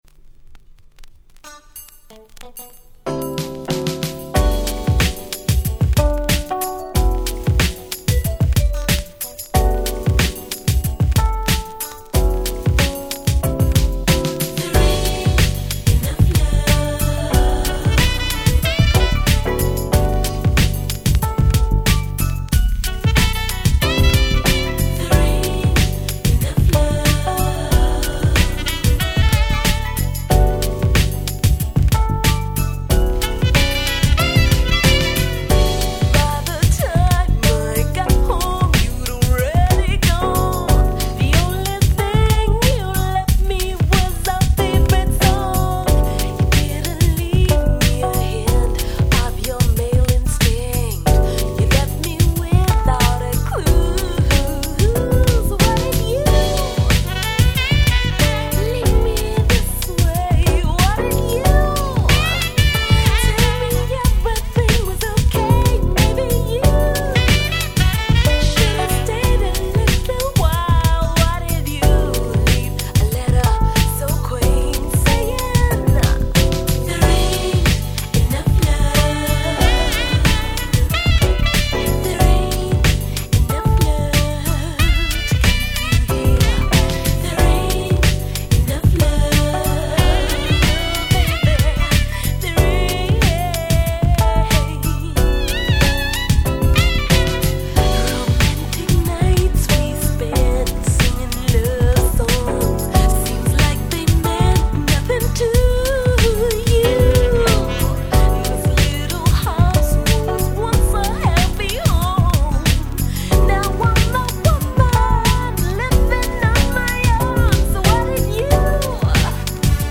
94' Nice UK Soul.